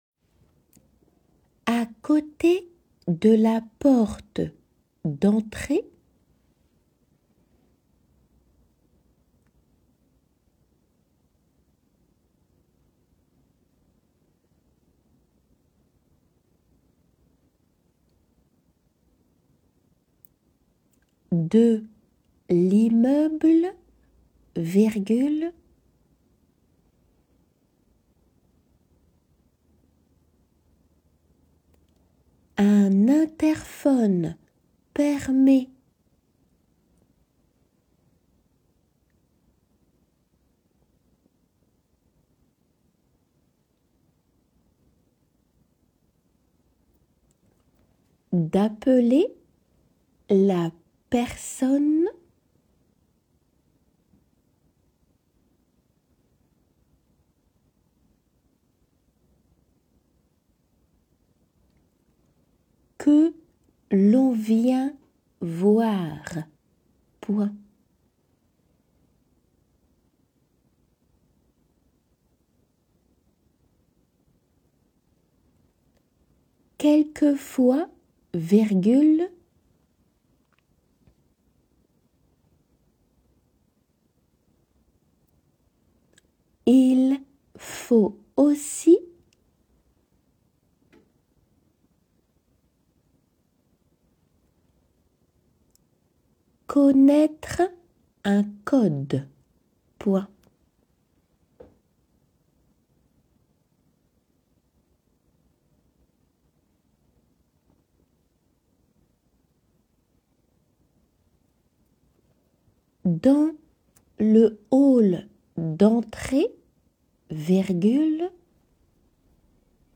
仏検　2級　デクテ　音声 La maison
デクテの速さで読まれています。